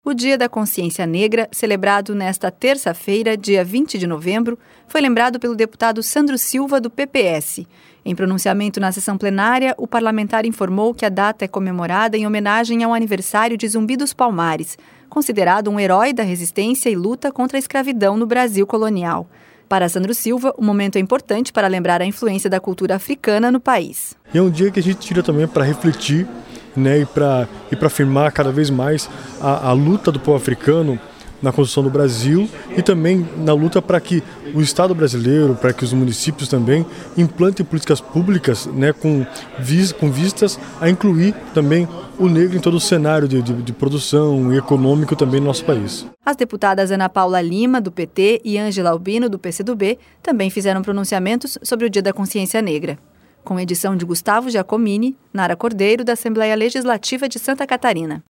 O Dia da Consciência Negra, celebrado nesta terça-feira, dia 20 de novembro, foi lembrado pelo deputado Sandro Silva (PPS). Em pronunciamento na sessão plenária, o parlamentar informou que a data é comemorada em homenagem ao aniversário de Zumbi dos Palmares, considerado um heroi da resistência e luta contra a escravidão no Brasil Colonial. Para Sandro Silva, o momento é importante para lembrar a influência da cultura africana no Brasil.